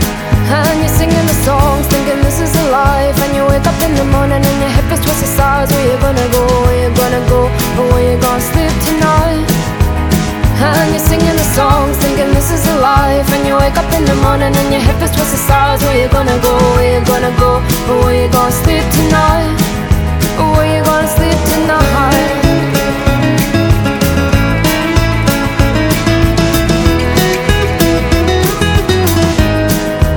Gattung: Moderner Einzeltitel
Besetzung: Blasorchester
für Blasorchester (mit Gesang) arrangiert hat.